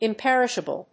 音節im・per・ish・a・ble 発音記号・読み方
/ìmpérɪʃəbl(米国英語)/